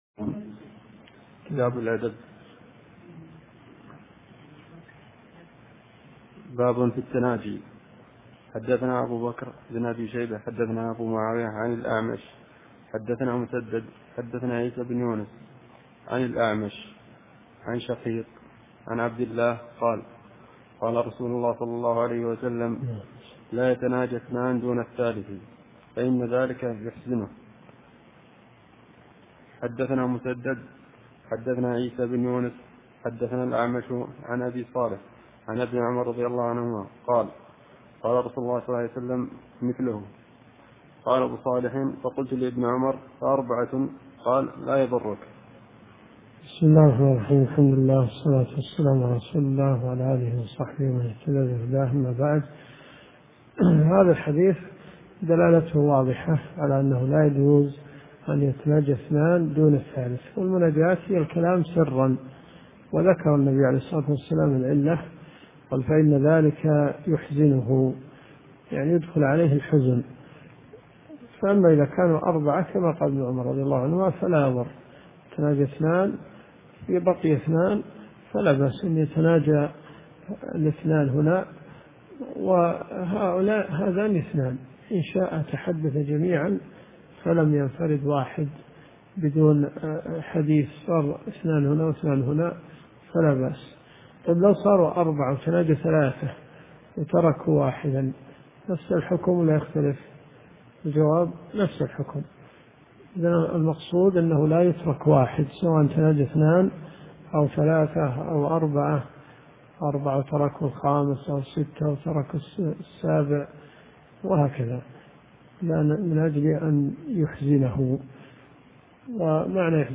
الرئيسية الدورات الشرعية [ قسم الحديث ] > سنن أبي داود . 1428 .